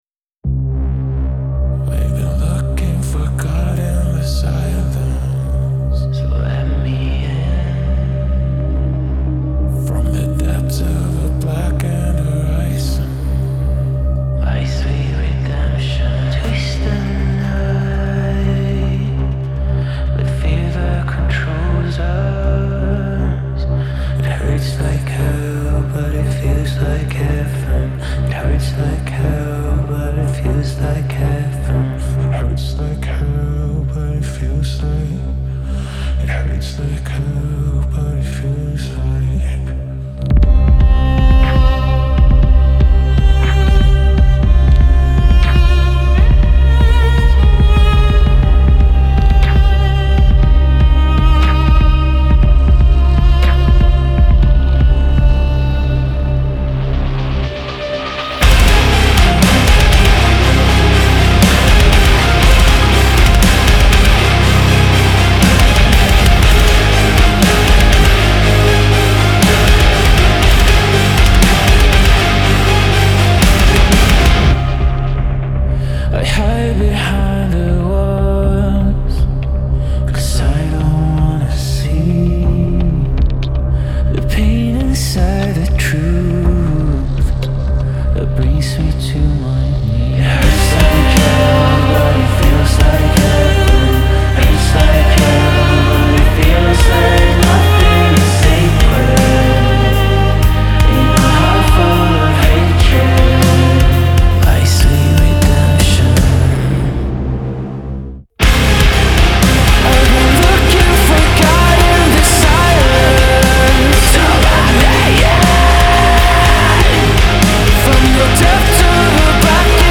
металкор
скрипку